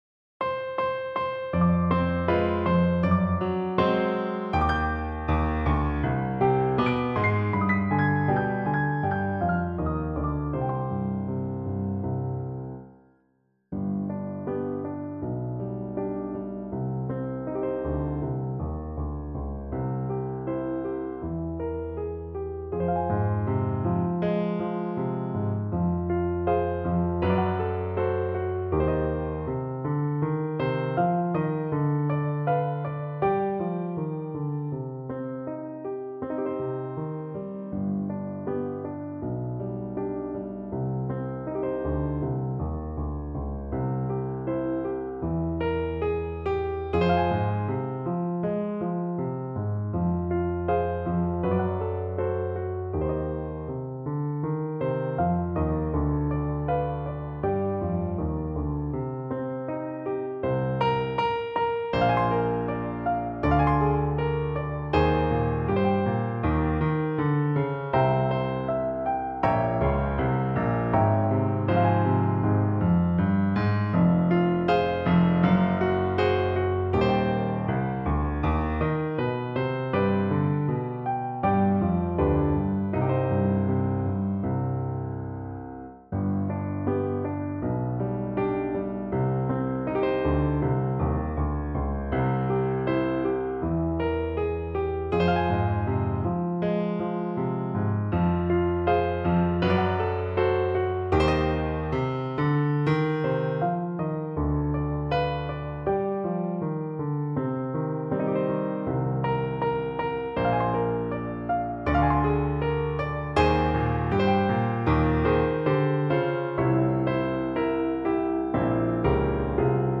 Trombone
C minor (Sounding Pitch) (View more C minor Music for Trombone )
2/4 (View more 2/4 Music)
Andante
World (View more World Trombone Music)
Brazilian